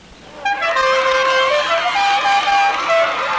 The Dixie horn used in One Armed Bandit